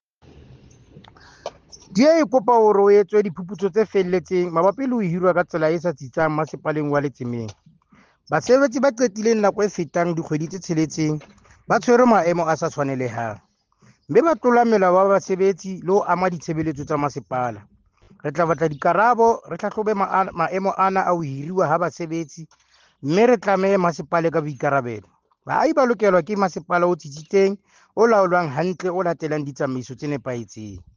Sesotho soundbites by Cllr Thabo Nthapo.